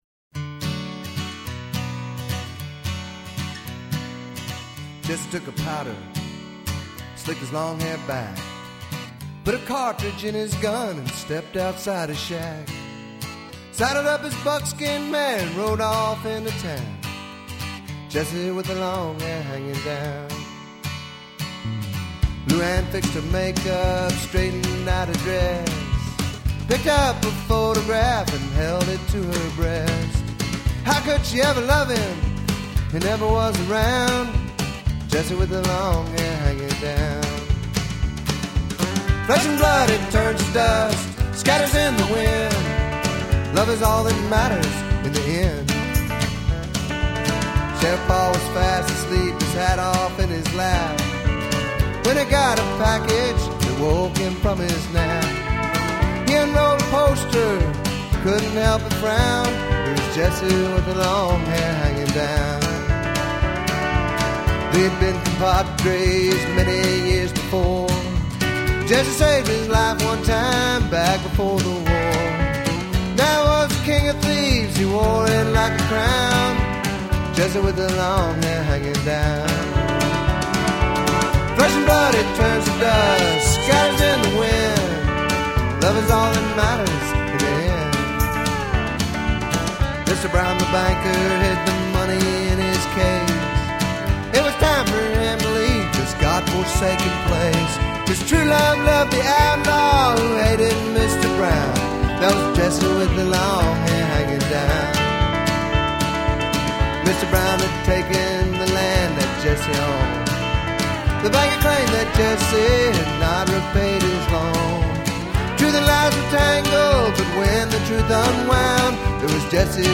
It is a country song.